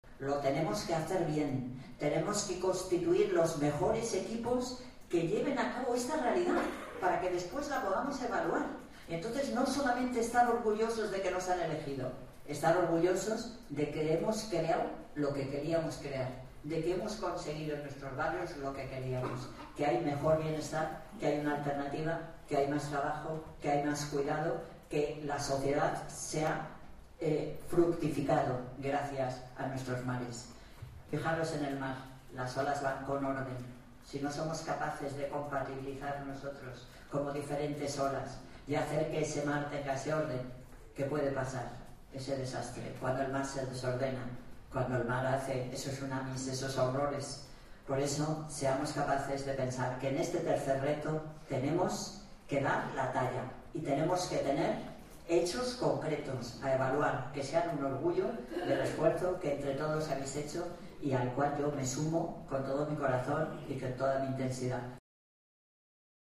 La alcaldesa de Madrid, Manuela Carmena, reflexiona sobre las posibilidades tan positivas que puede ofrecer la economía social Carmena apunta que para que la economía social y MARES funcione, es necesario articular los equipos de personas y cohesionar los barrios